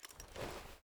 gamedata / sounds / weapons / ace52 / holster.ogg
holster.ogg